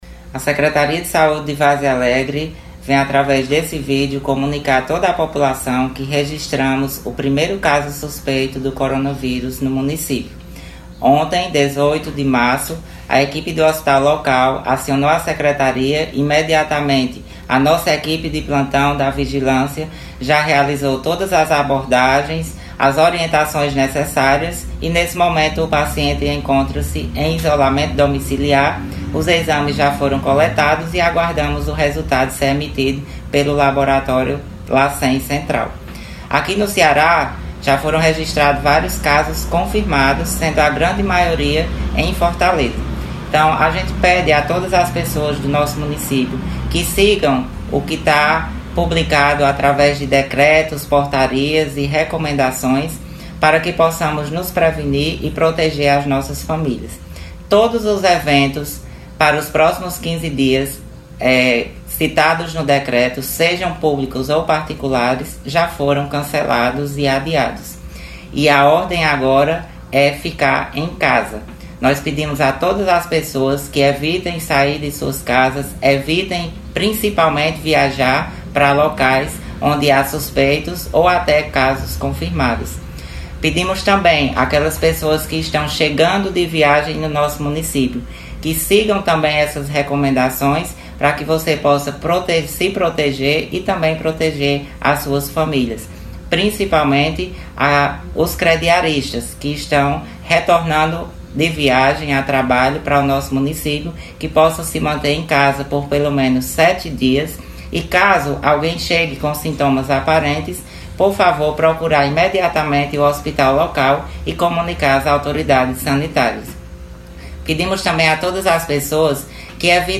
A Secretaria de Saúde, por meio do seu secretário Ivo Leal, se posiciona sobre o primeiro caso suspeito de coronavírus em Várzea Alegre.